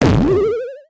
Cannon Fire Clash Of Clans Sound Effect Free Download
Cannon Fire Clash Of Clans